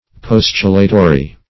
Search Result for " postulatory" : The Collaborative International Dictionary of English v.0.48: Postulatory \Pos"tu*la*to*ry\, a. [L. postulatorius.]